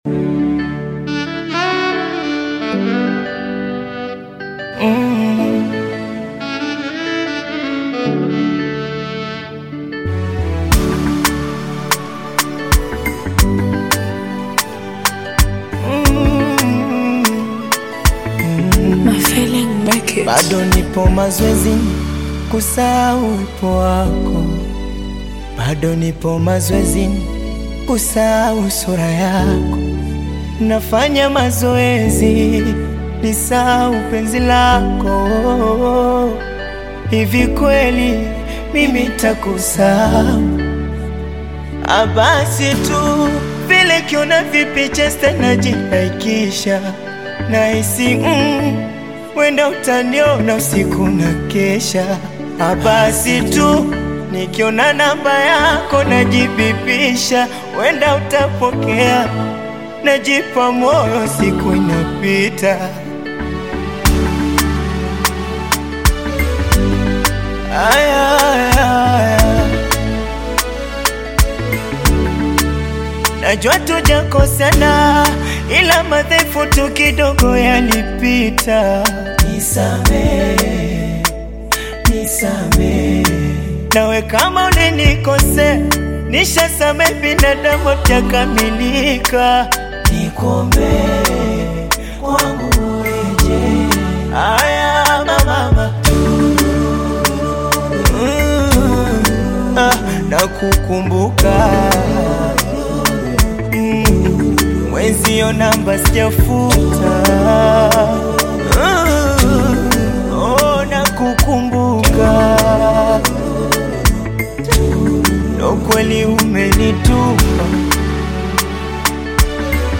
soulful Afrobeats/Bongo Flava single